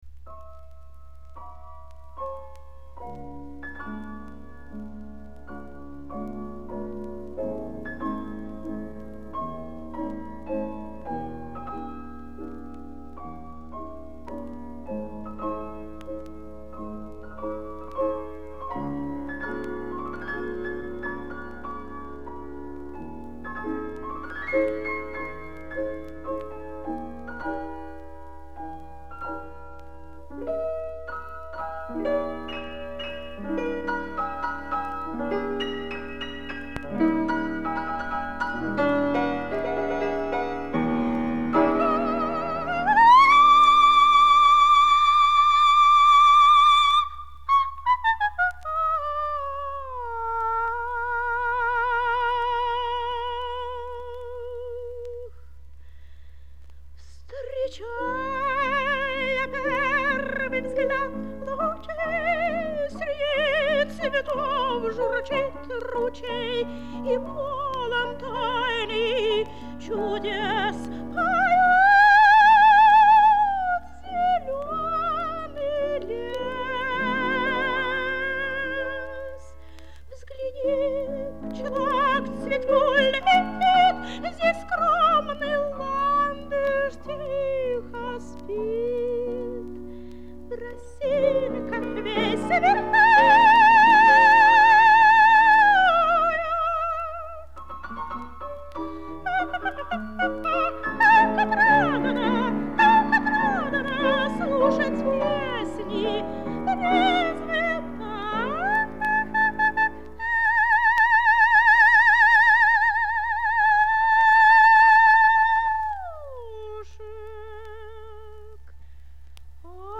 фортепьяно